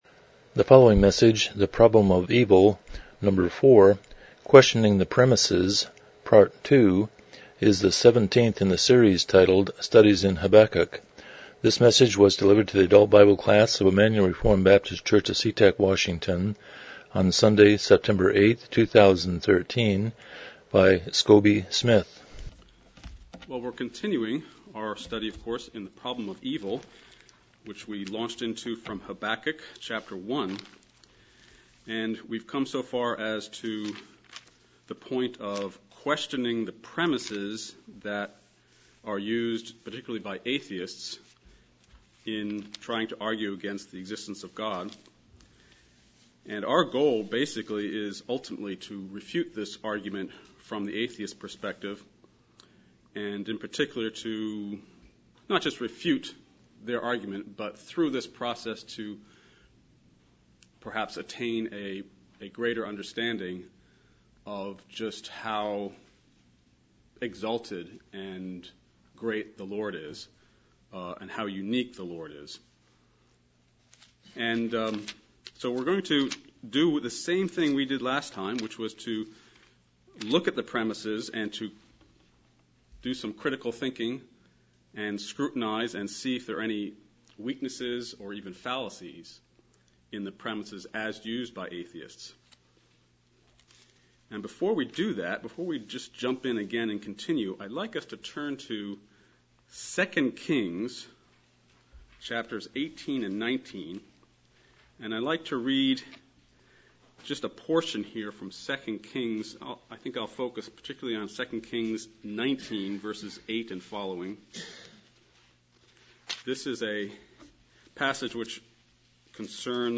Series: Studies in Habakkuk Service Type: Sunday School